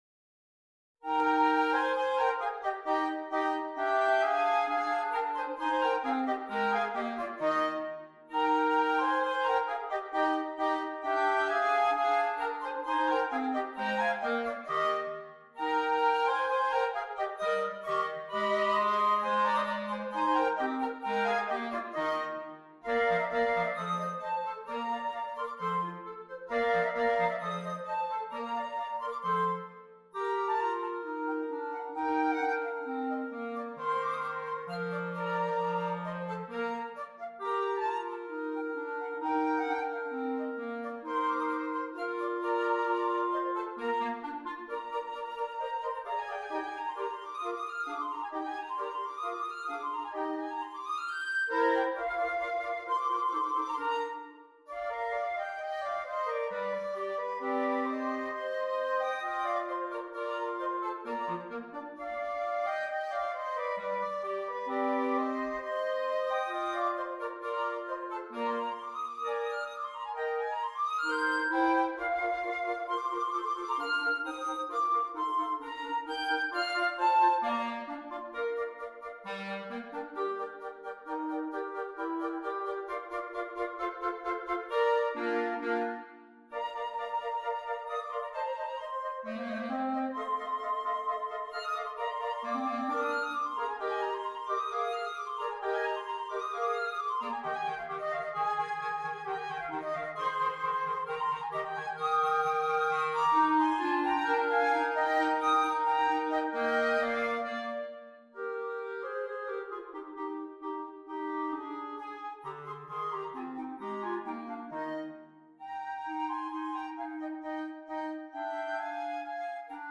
2 Flutes, 2 Clarinets
woodwind ensemble of 2 flutes and 2 clarinets